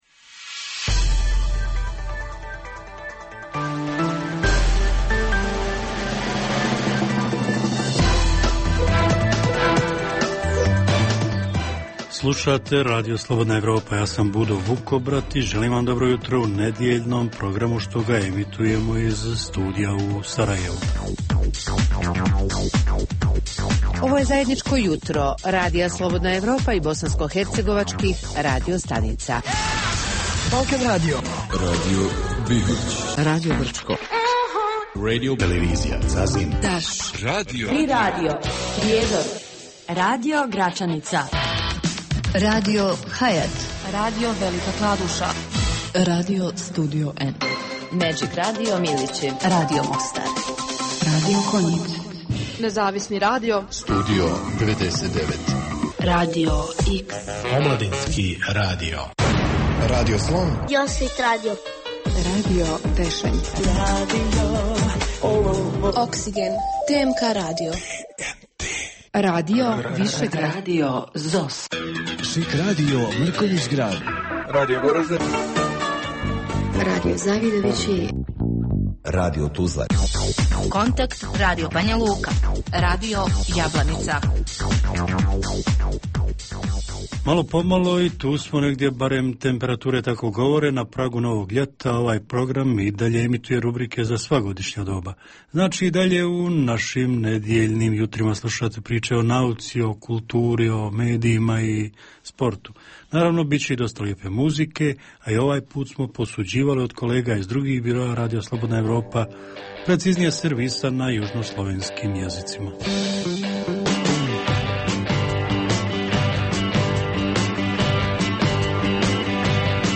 Jutarnji program namijenjen slušaocima u Bosni i Hercegovini. Sadrži intervju